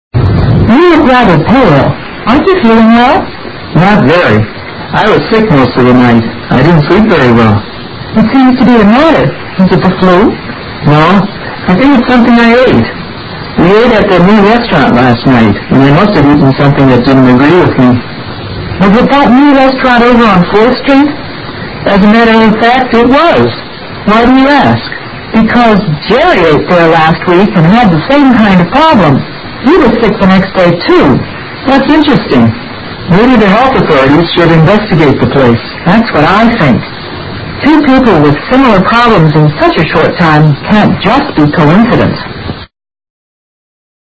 Dialogue 20